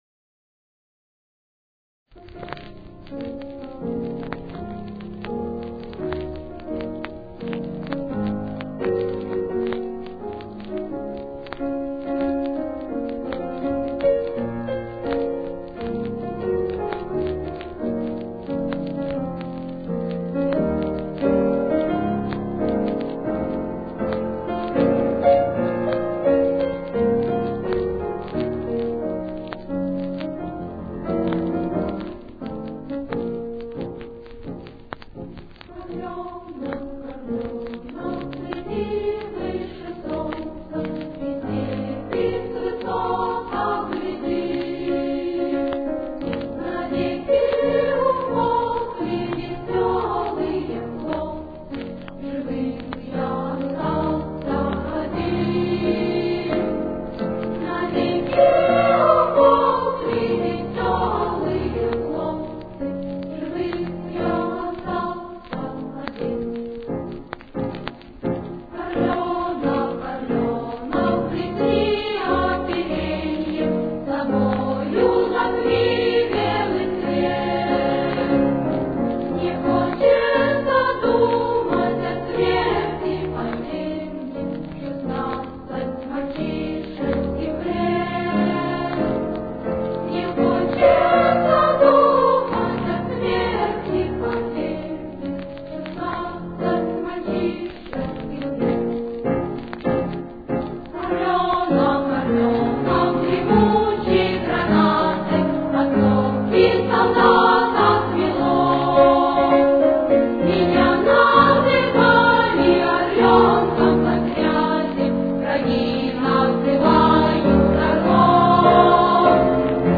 Си-бемоль минор. Темп: 93.